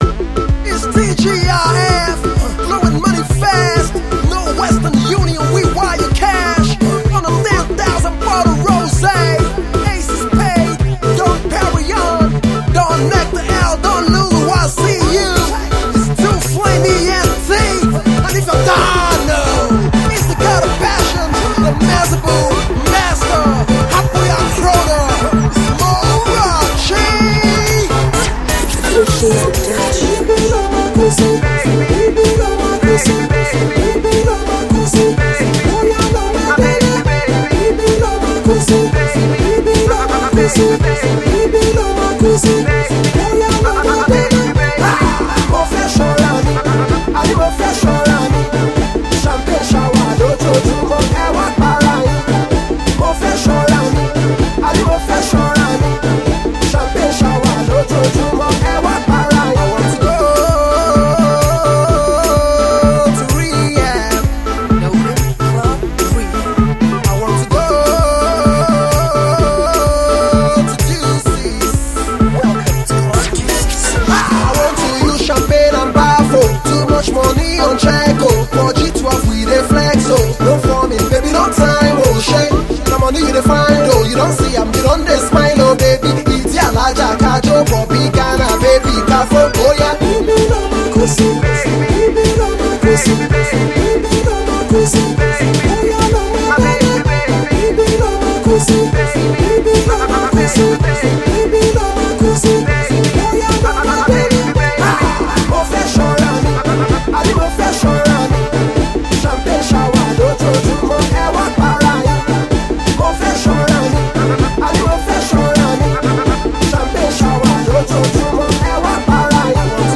club banger that will keep you body rocking